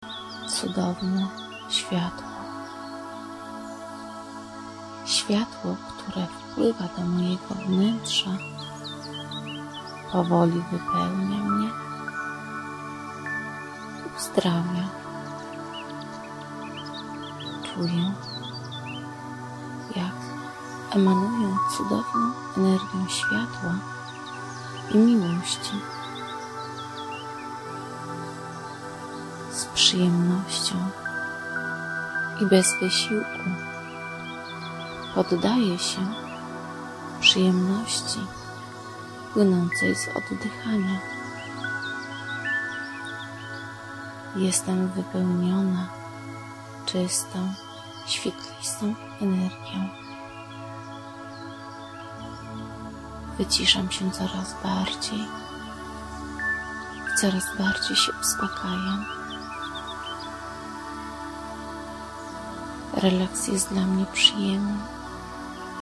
Po�� si� i ws�uchuj�c si� w g�os osoby prowadz�cej, powtarzaj w my�lach wypowiadane przez ni� s�owa.
27 listopada 2009 Dwie niezwykle przyjemne medytacje: inspiruj�ca tre��, �agodny i wyra�ny g�os lektorki, spokojna muzyka i �piew ptak�w w tle.